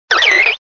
Fichier:Cri 0420 DP.ogg